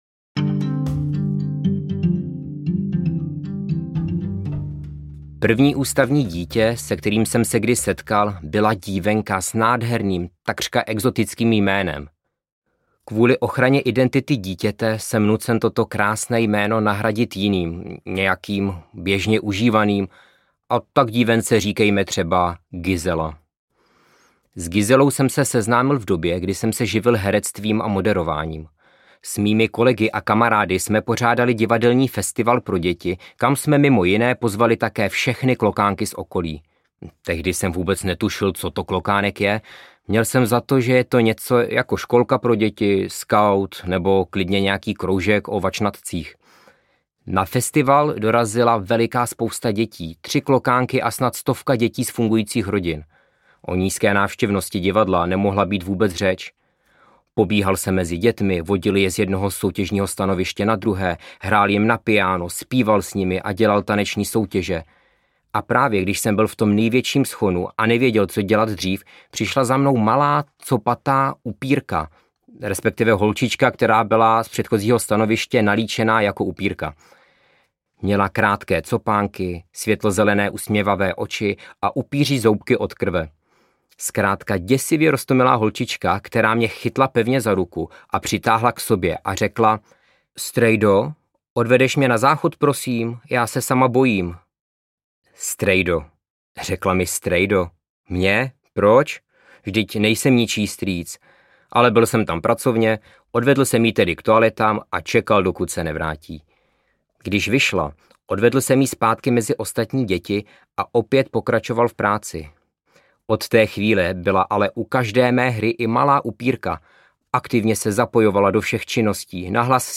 Ukázka z knihy
Vyrobilo studio Soundguru.